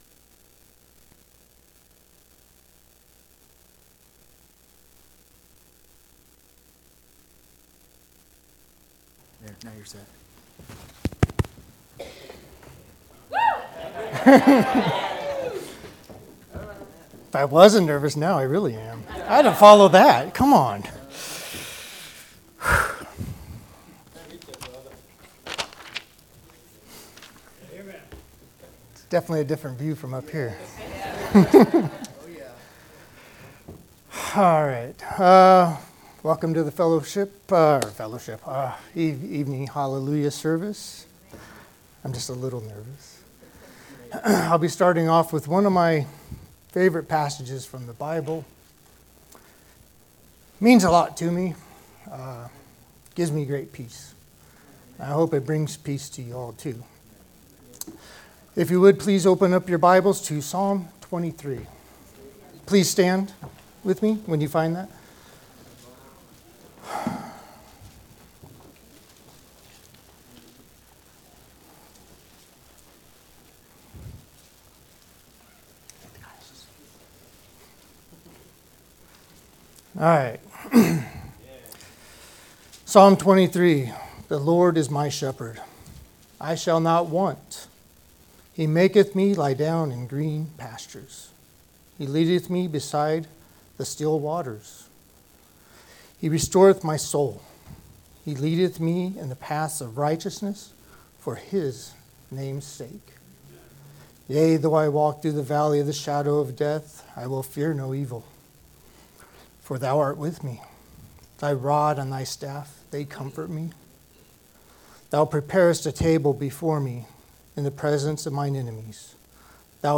2025 Hallelujah Service Guest Preacher I Kings